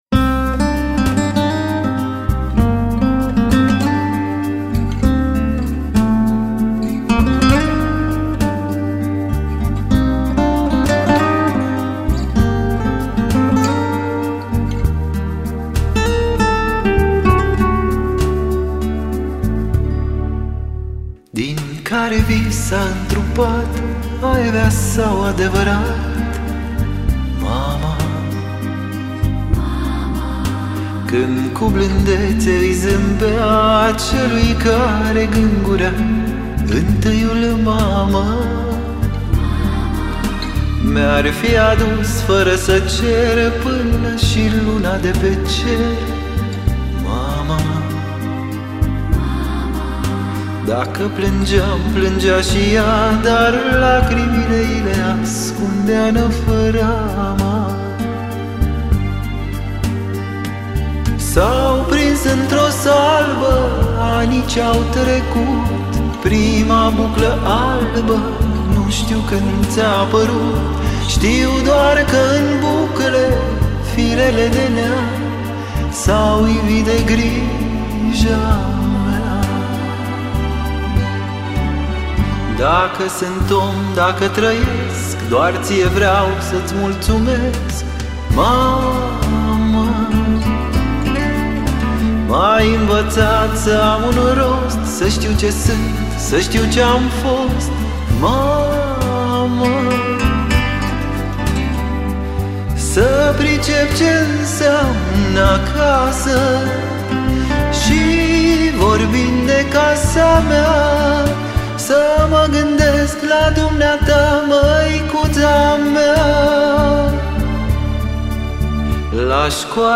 muzică uşoară